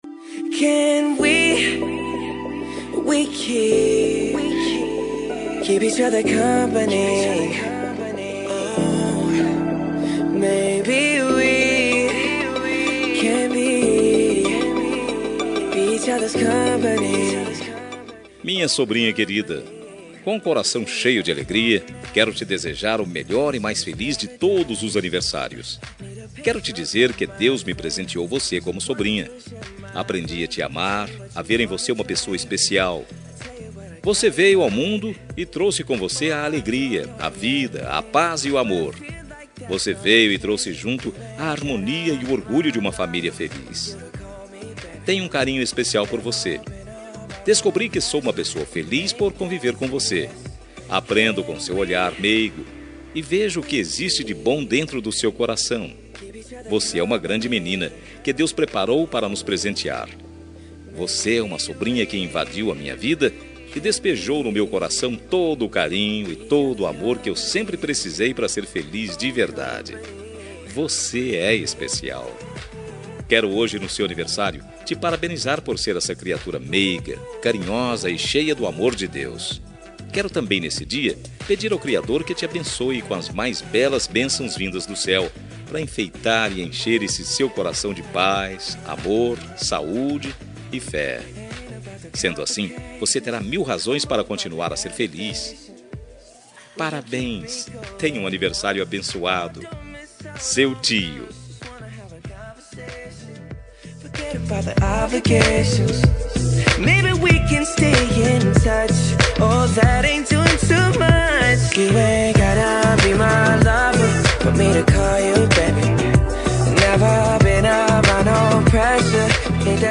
Aniversário de Sobrinha – Voz Feminina – Cód: 4285